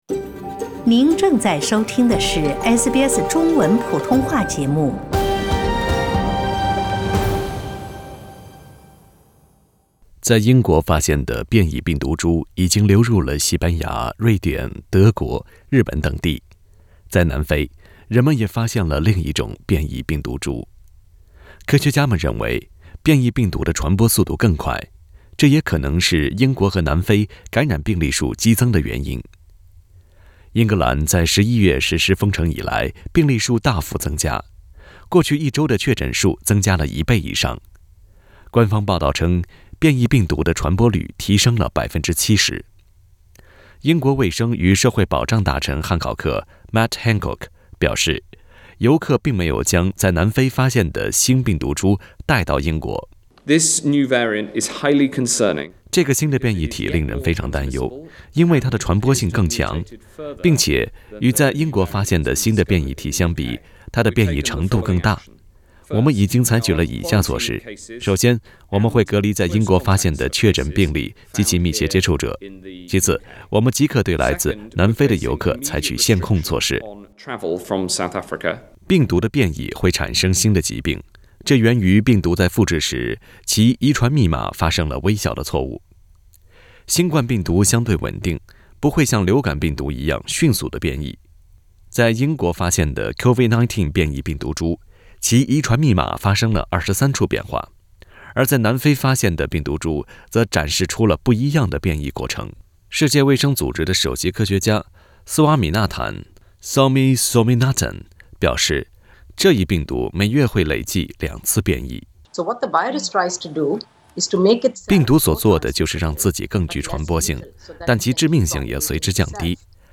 （點擊圖片音頻，收聽完整寀訪。）